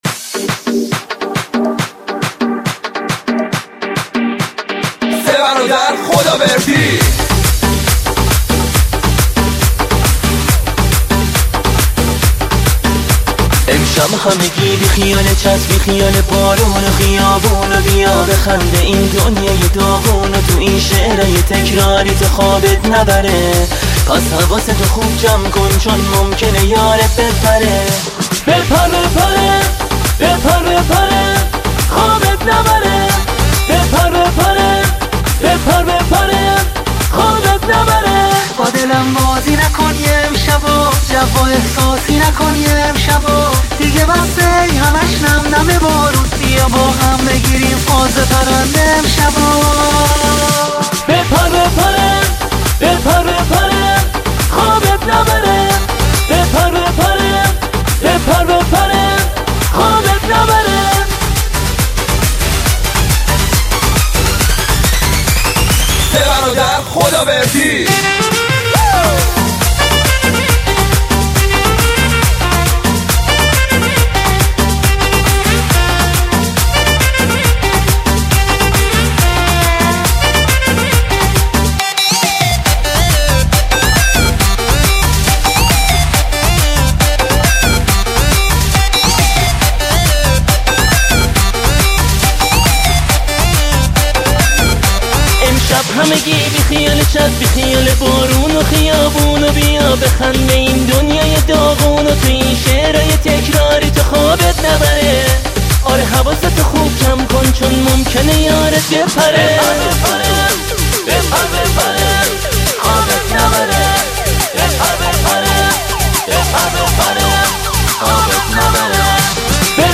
آهنگ های شاد ویژه شب یلدا